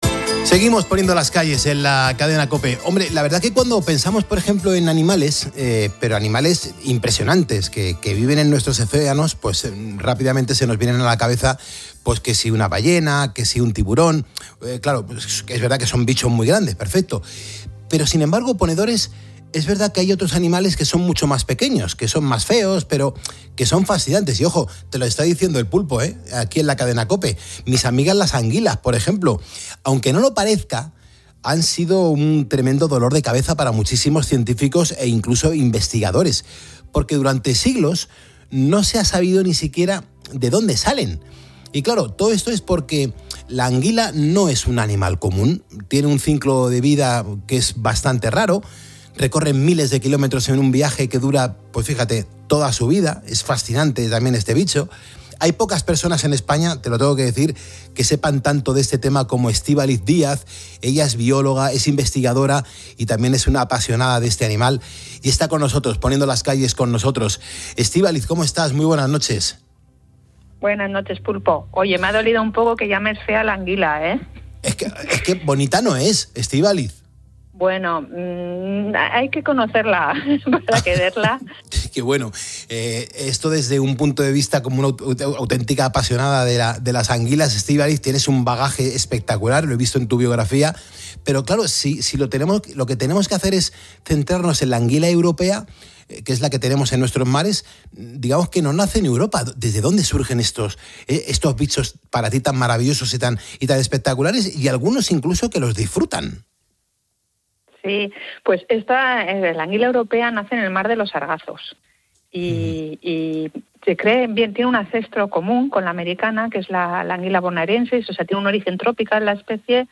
un enigma de siglos Durante la entrevista